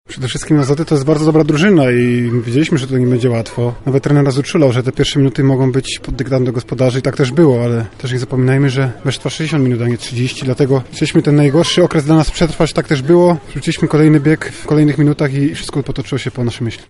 Mówi rozgrywający Vive Krzysztof Lijewski.